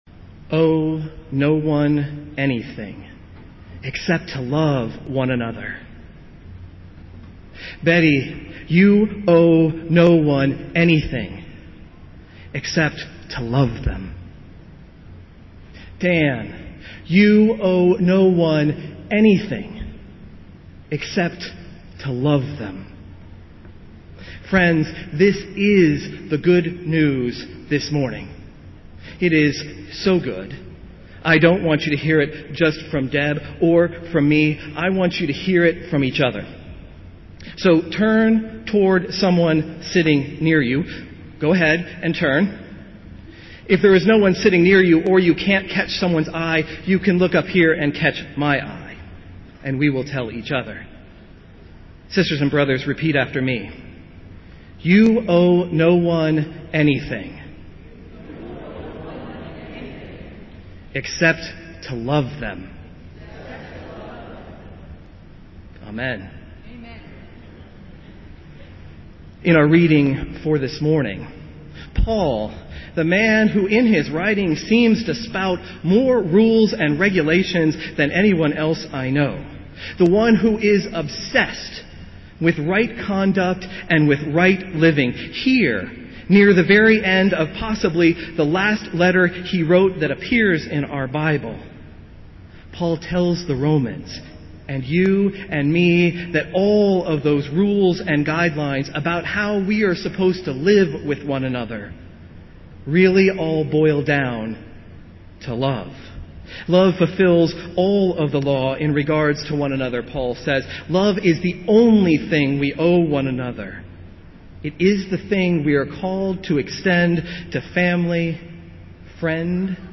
Festival Worship - Twelfth Sunday after Pentecost